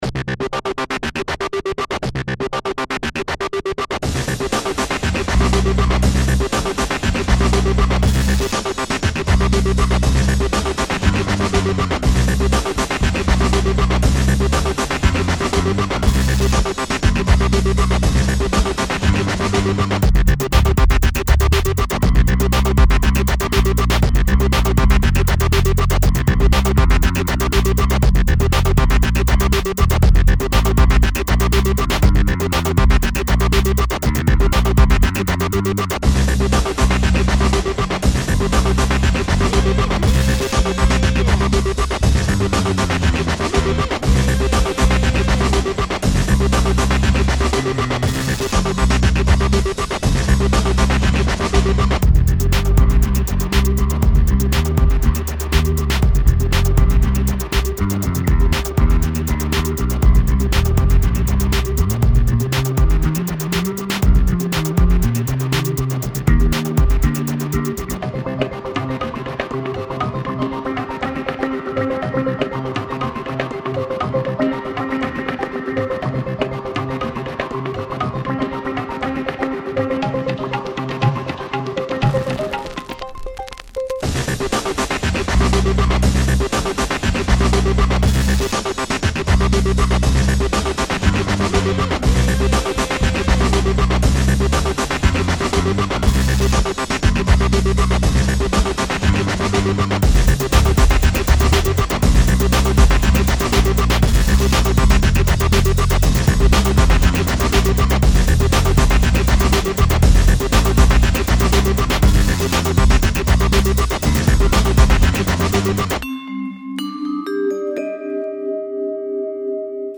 MOONPATROL - HIGH ENERGY ROCK'N'ROLL
Klampfe einstecken, Schlagzeug aussuchen und Aufnahme drücken.
Damit wäre die Stimmung der Nacht dann eingefangen und die Wache wurde zum 6-Stunden-Jam mit den Maschinen.